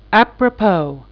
aprə'pəv,